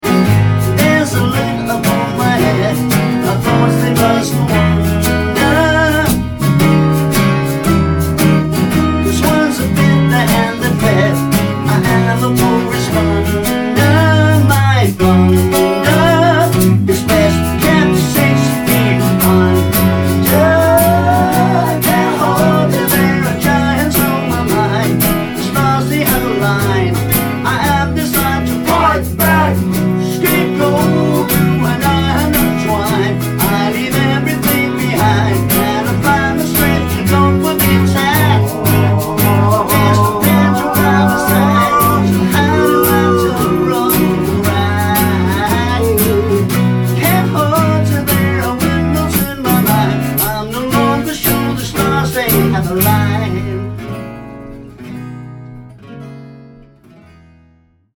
• Indie
• Pop
• Rock
• Rock and roll